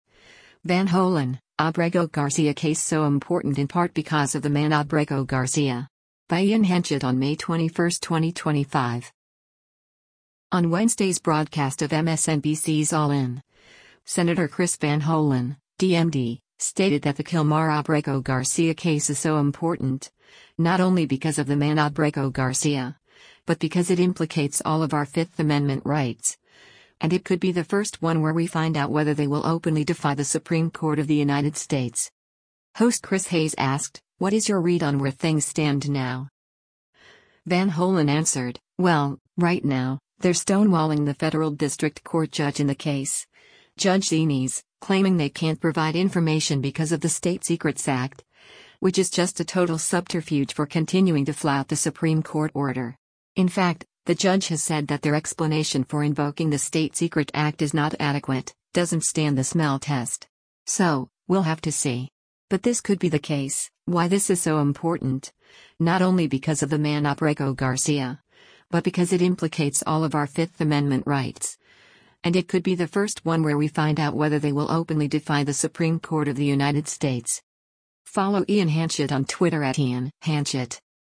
Video Source: MSNBC
Host Chris Hayes asked, “What is your read on where things stand now?”